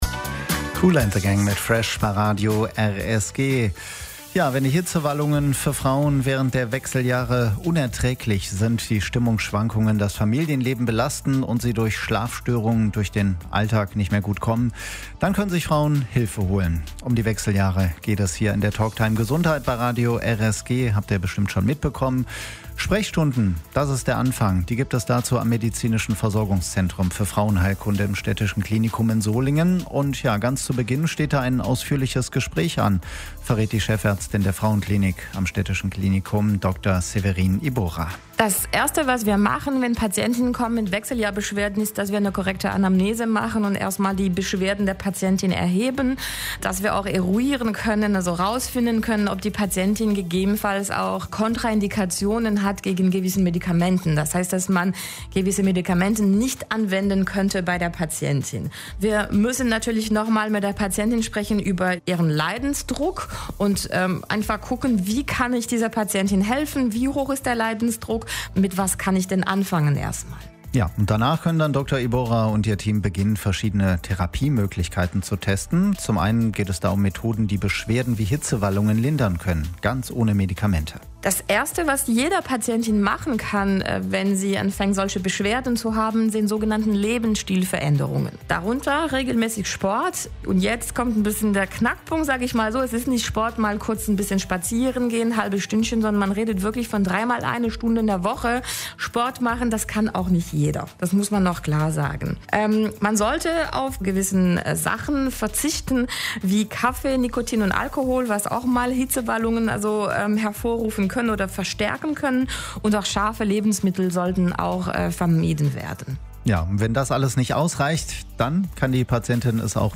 Die Talktime Gesundheit lief am Samstag, 21. Juni, von 12 bis 13 Uhr bei Radio RSG - wenn ihr etwas runterscrollt, könnt die Sendung hier noch einmal hören.